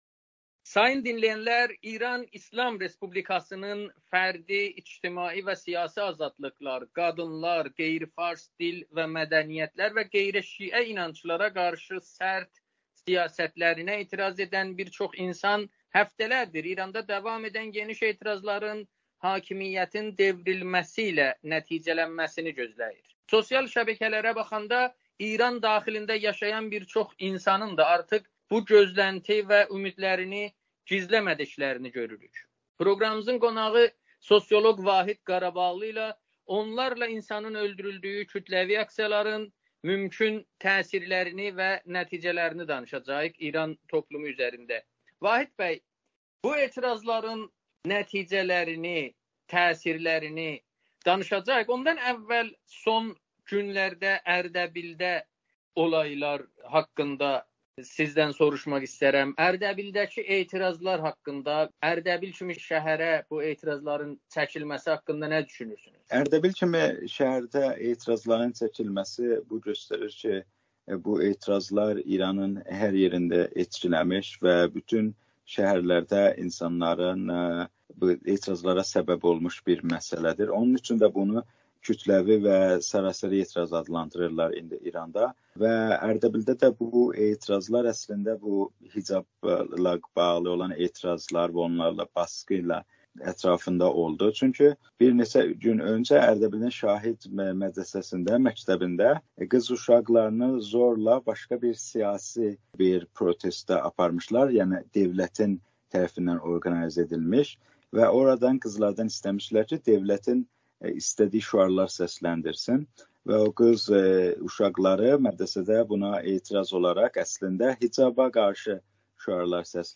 Amerikanın Səsinə müsahibədə İranda həftələrdir qadınların başçılığı ilə davam etməkdə olan geniş etirazların mümkün nəticələri, habelə İran toplumu üzərində göstərdiyi və ya göstərəcəyi siyasi-ictimai təsirləri haqqında danışlb.